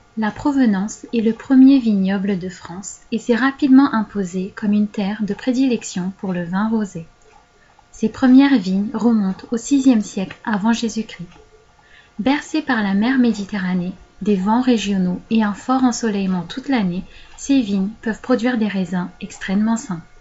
Voix off
voix off